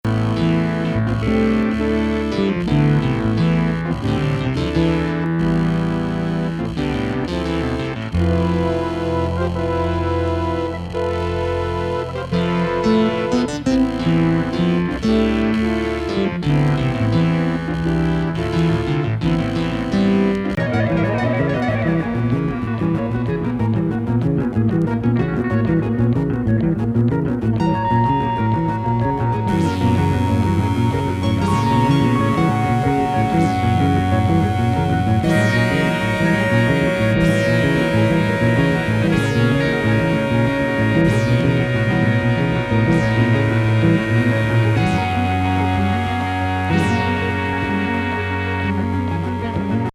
なシンセサイザー・ワークがくせになる
レフティ・プロッグ・グルーヴ